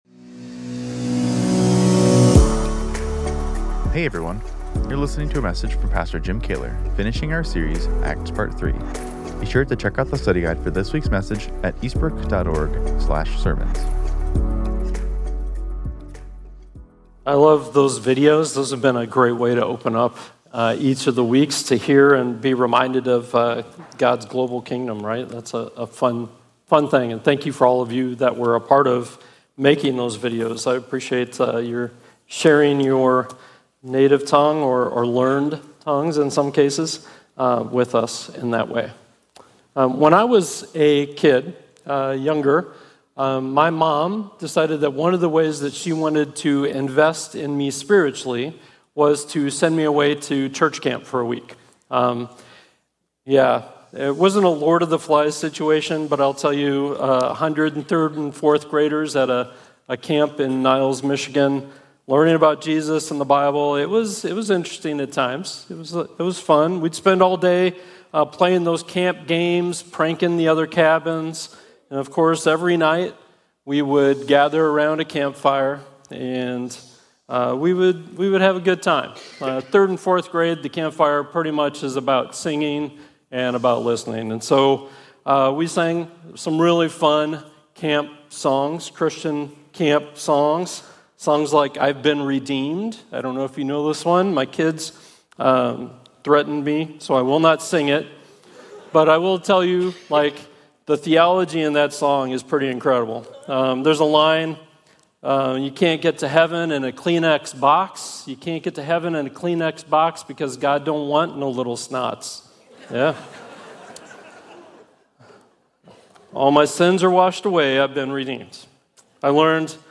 Sermon Outline Because Jesus is the King of kings, He is also Lord of our life, which gives us a story to share.